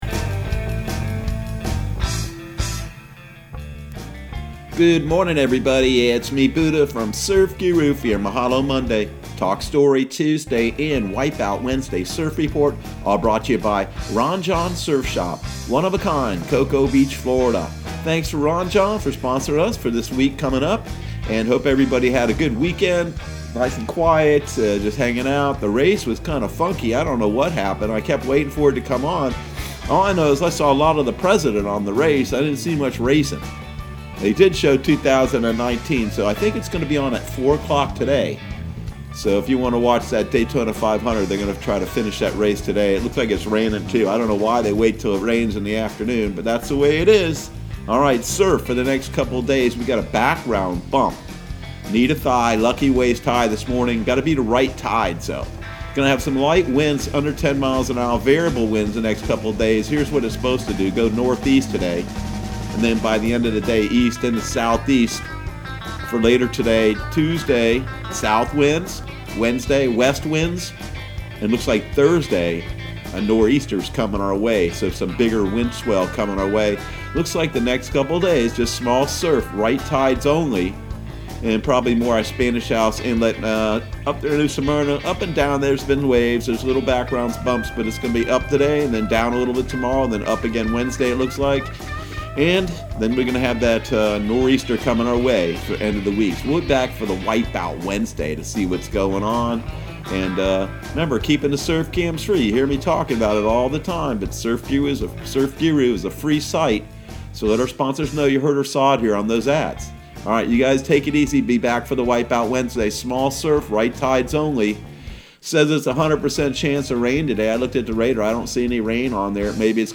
Surf Guru Surf Report and Forecast 02/17/2020 Audio surf report and surf forecast on February 17 for Central Florida and the Southeast.